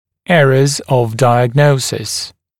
[‘erəz əv ˌdaɪəg’nəusɪs] [‘эрэз ов ˌдайэг’ноусис] ошибки диагностики